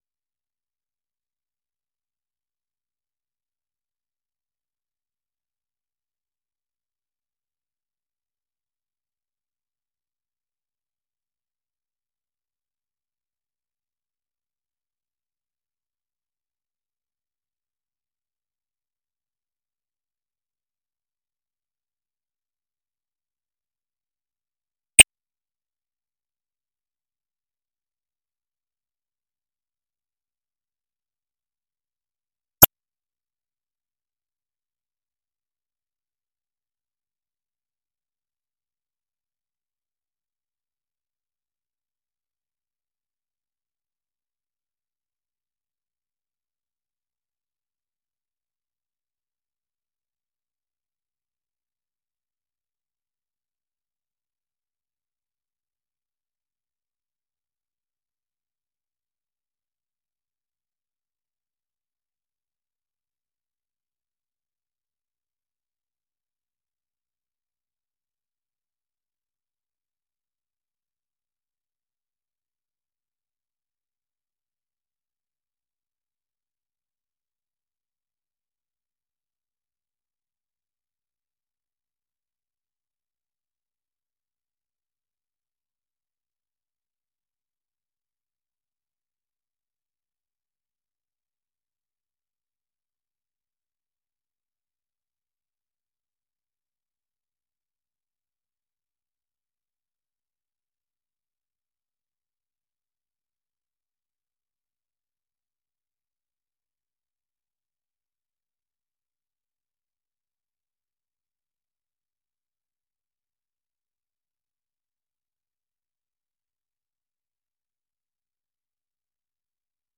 Listen Live - 粵語廣播 - 美國之音
The URL has been copied to your clipboard 分享到臉書 分享到推特網 No media source currently available 0:00 0:30:00 0:00 下載 128 kbps | MP3 64 kbps | MP3 時事經緯 時事經緯 分享 時事經緯 分享到 美國之音《時事經緯》每日以30分鐘的時間報導中港台與世界各地的重要新聞，內容包括十分鐘簡短國際新聞，之後播出從來自世界各地的美國之音記者每日發來的採訪或分析報導，無論發生的大事與你的距離是遠還是近，都可以令你掌握與跟貼每日世界各地發生的大事！